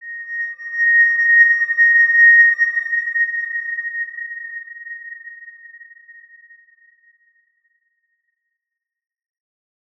X_Windwistle-A#5-pp.wav